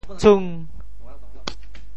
存 部首拼音 部首 子 总笔划 6 部外笔划 3 普通话 cún 潮州发音 潮州 cung5 文 中文解释 存 <动> (形声。